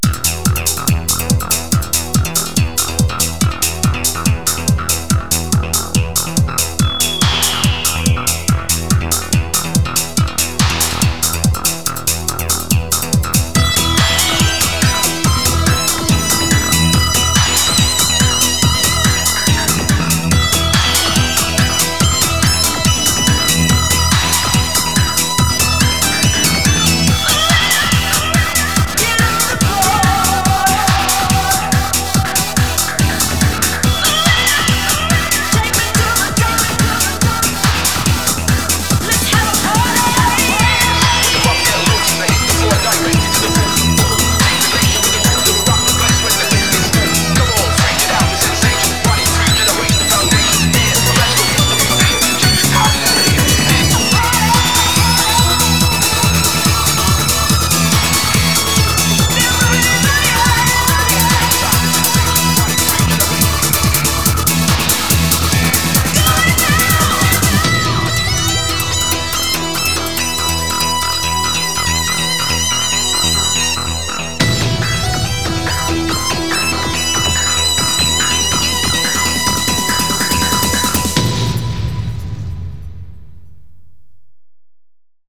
BPM142
Audio QualityPerfect (High Quality)
Better quality audio.